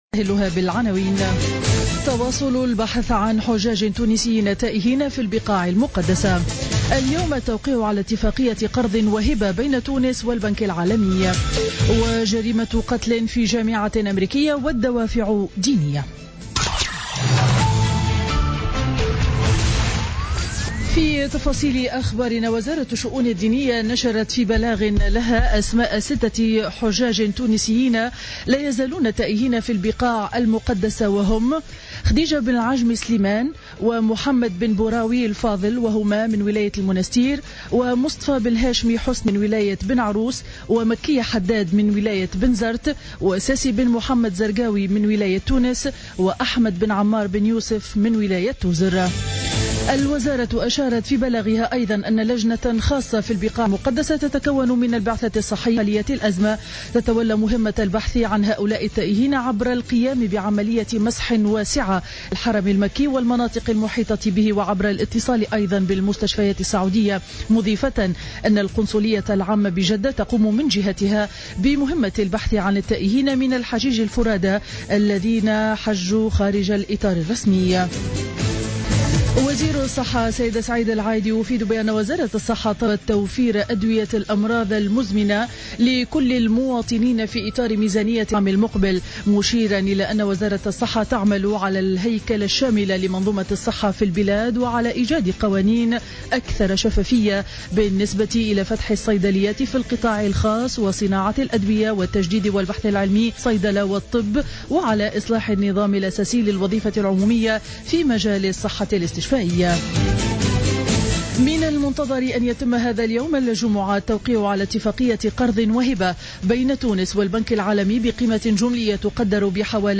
نشرة أخبار السابعة صباحا ليوم الجمعة 2 أكتوبر 2015